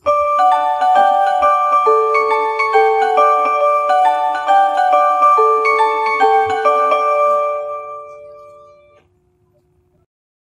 • reverted font change due to compatibility, added japan rail jingle on page load, buttons section now scrolls